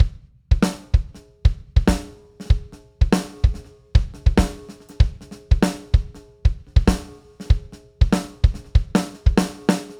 スネア
スネアは明るくスコーンと抜けてくる非常に目立つ音であるため、スネアをどのタイミングで叩くかによって感じられるリズムは大きく変わってきます。
こんなふうに、合間合間に等間隔でスパーンと鳴らすのが、ポピュラー音楽でいちばん定番のリズムスタイルです。
r1-essentials-snare.mp3